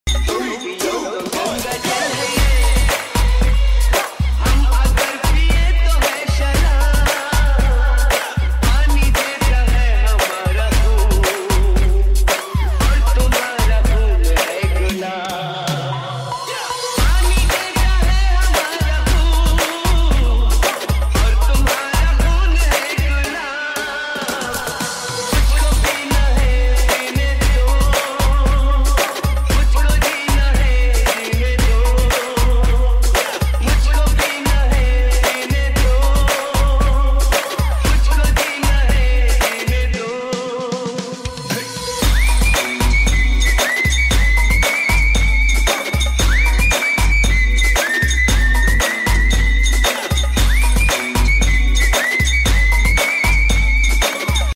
Trap Mix